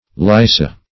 Lyssa \Lys"sa\ (l[i^]s"s[.a]), n. [NL. See Lytta.] (Med.)